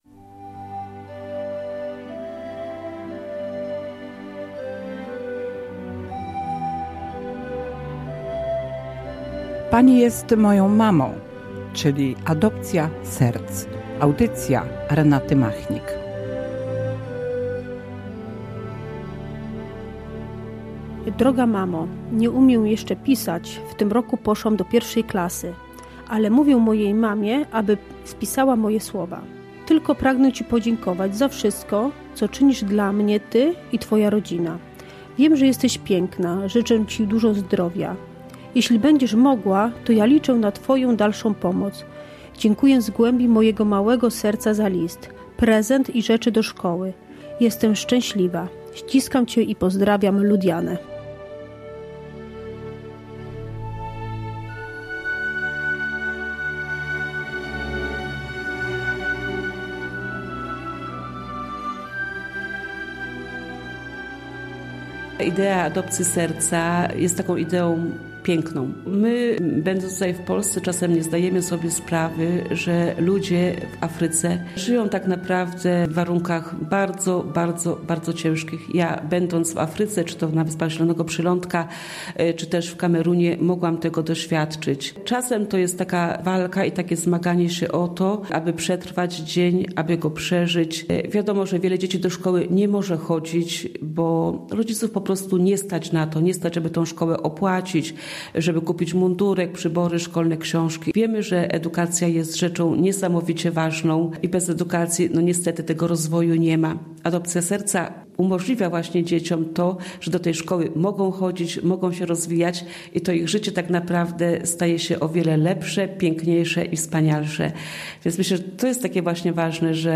Adopcja Serca - audycja